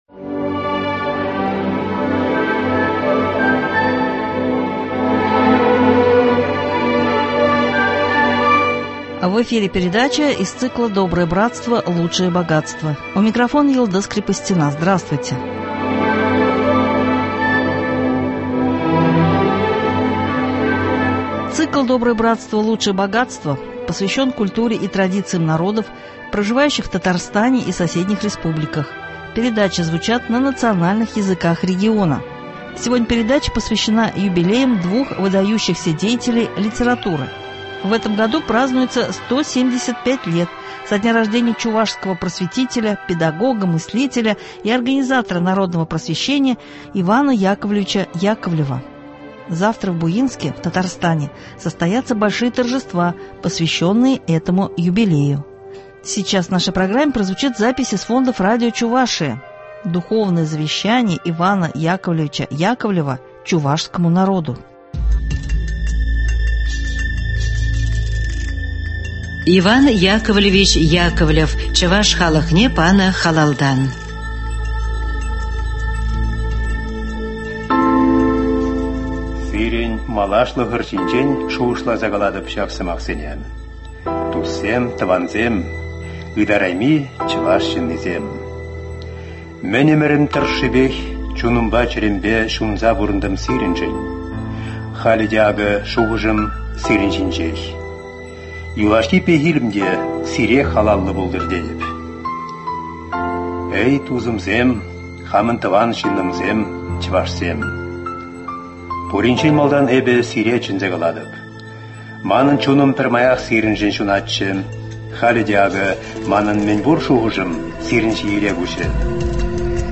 Сейчас в нашей программе прозвучит запись из фондов радио Чувашии — Духовное завещание Ивана Яковлевича Яковлева чувашскому народу Далее – о юбилее следующего года.